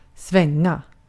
Ääntäminen
US : IPA : [sweɪ]